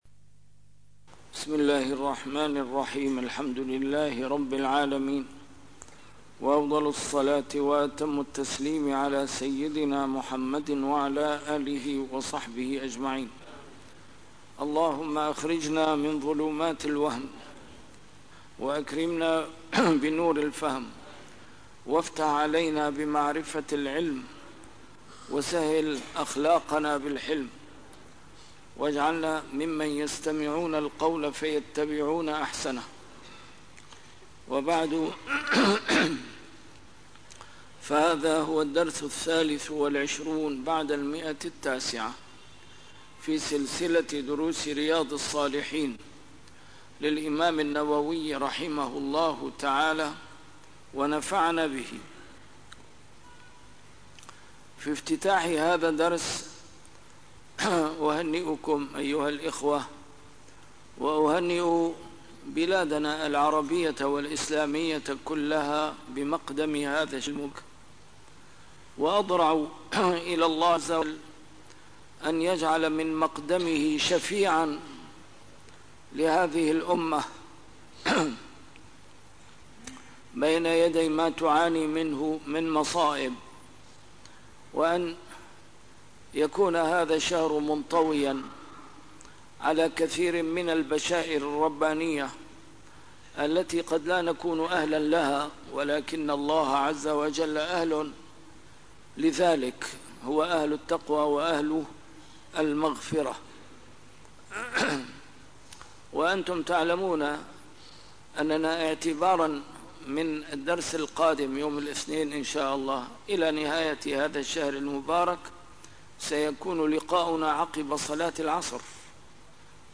A MARTYR SCHOLAR: IMAM MUHAMMAD SAEED RAMADAN AL-BOUTI - الدروس العلمية - شرح كتاب رياض الصالحين - 923- شرح رياض الصالحين: تحريم الرياء - ما يتوهم أنه رياء - تحريم النظر إلى المرأة الأجنبية